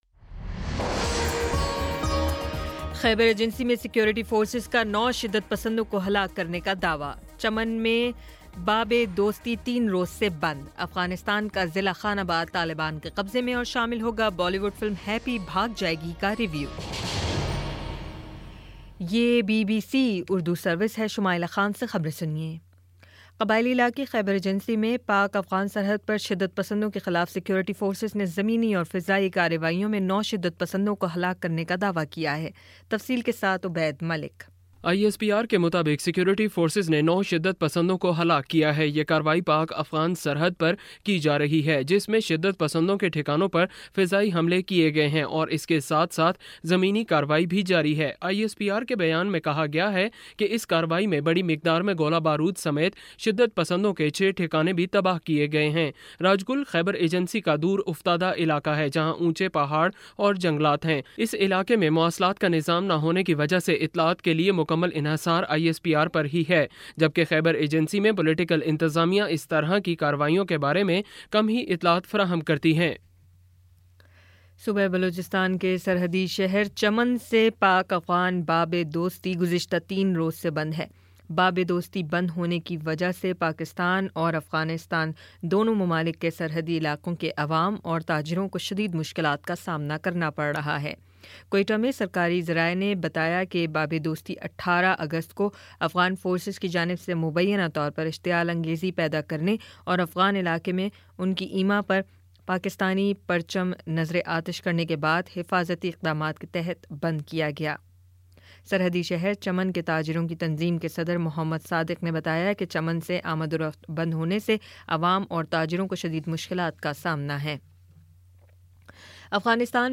اگست 20 : شام چھ بجے کا نیوز بُلیٹن